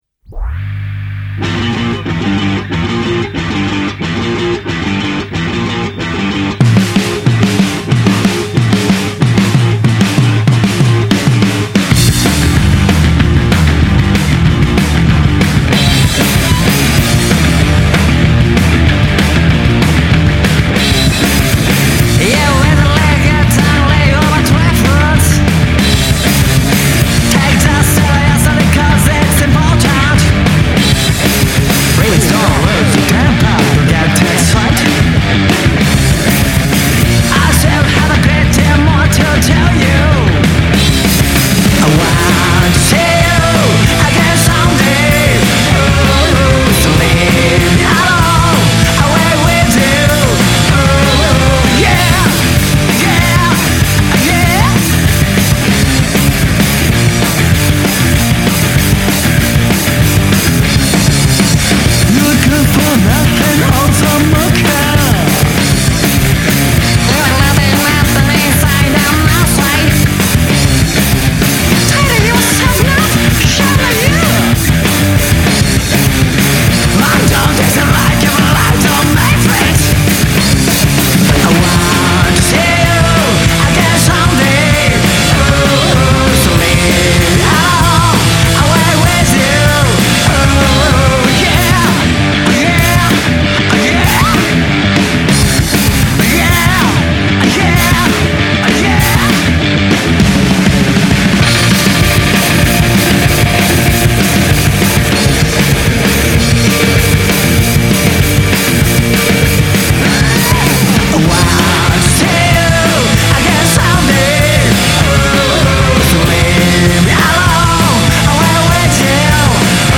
rock bands